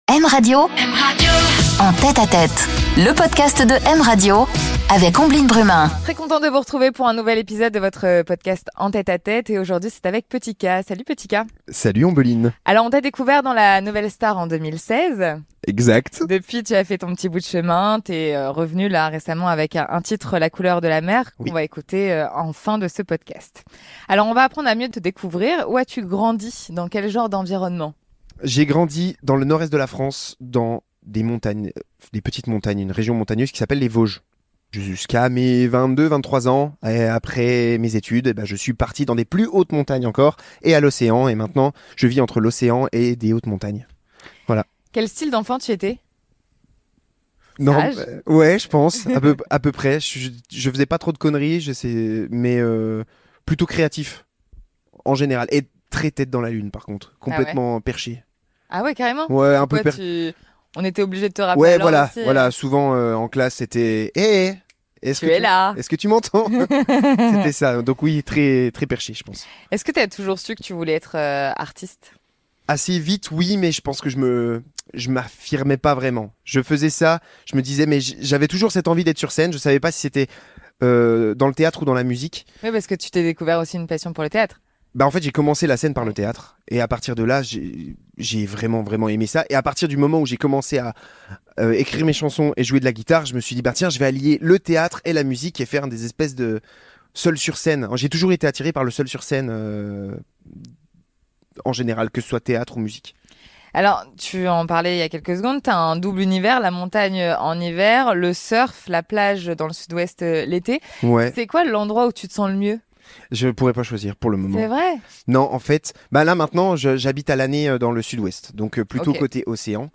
Une interview en confidence, dans l'intimité des artistes